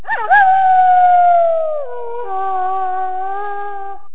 dog-howl-1.ogg